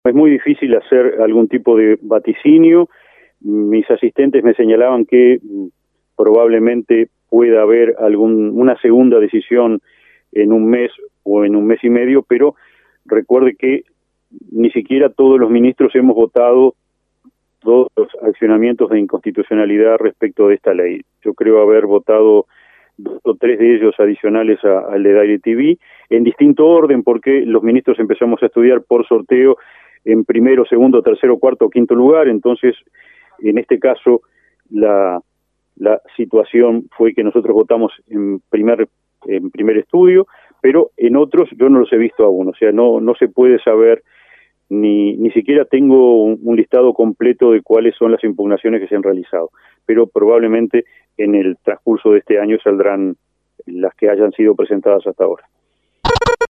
El Ministro Jorge Chediak agregó en el diálogo que es posible que el próximo fallo se de en un mes y medio. El abogado manifestó que se llevará por lo menos hasta julio terminar de analizar los diversos recursos.